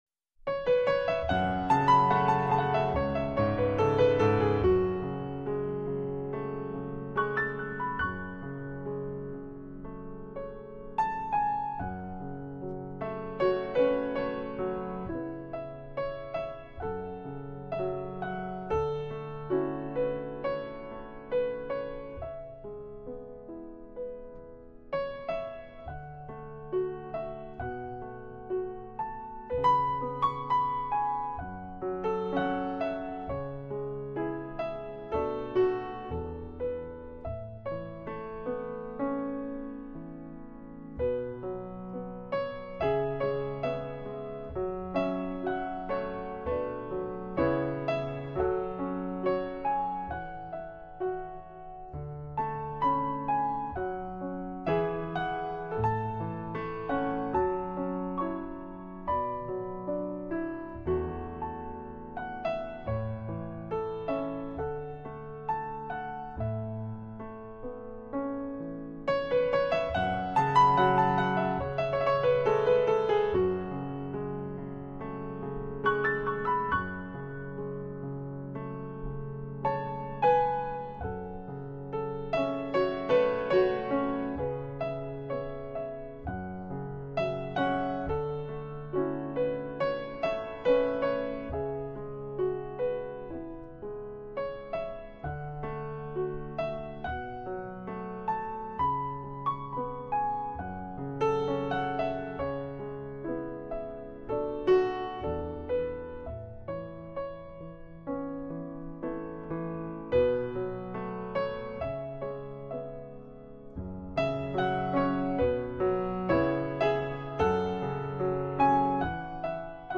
不同的编曲：浪漫、 轻松、激情....等旋律，亦是令你回味方才的音乐，你定会一再而再地往返这个 音乐世界。